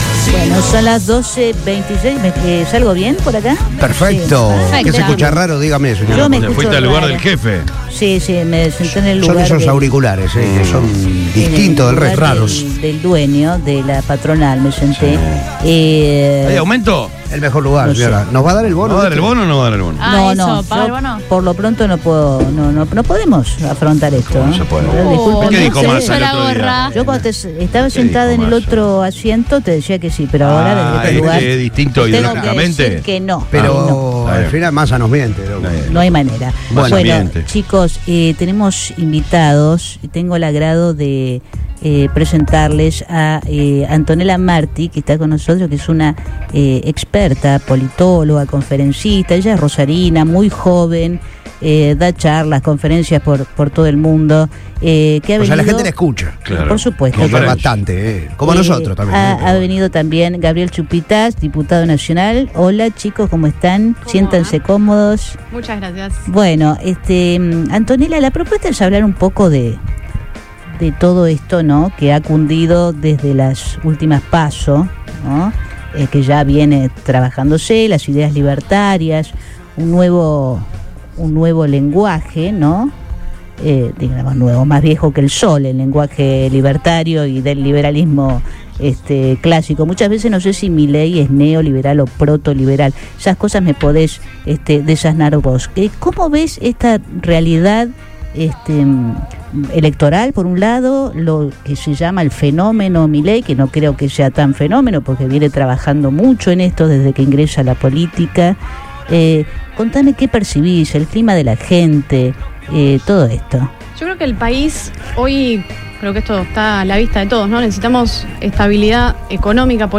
En Todo Pasa de Radio Boing, acompañada por el diputado nacional Gabriel Chumpitaz, analizó la situación política nacional y afirmó que las ideas del candidato de La Libertad Avanza son conservadoras.